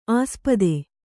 ♪ āspade